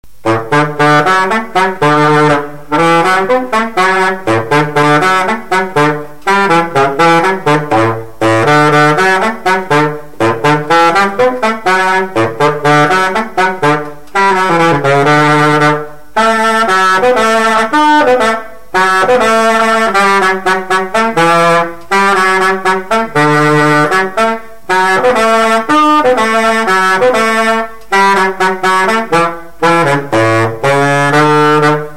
Résumé instrumental
gestuel : danse
Pièce musicale inédite